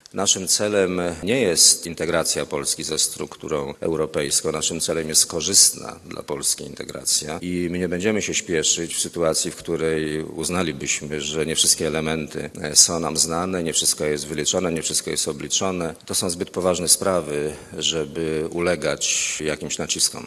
Musimy wybrać najbardziej korzystny system podziału unijnych pieniędzy - mówi premier Leszek Miller (636Kb)